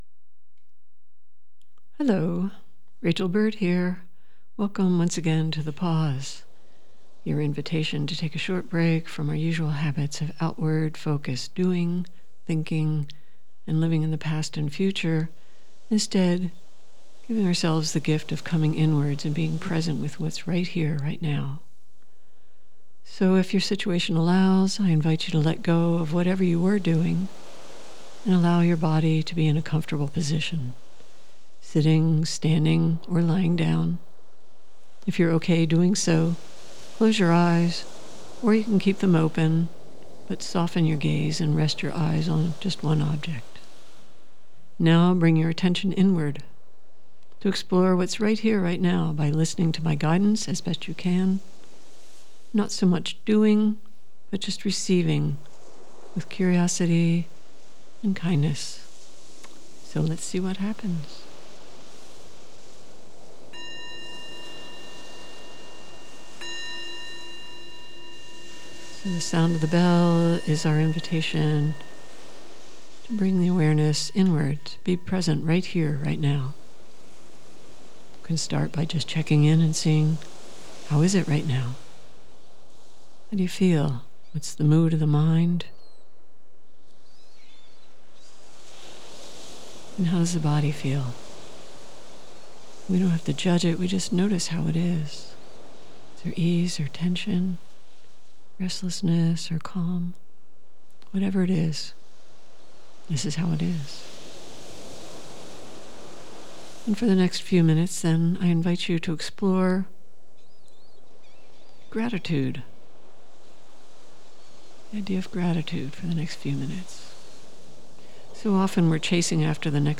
Meditation on Gratitude.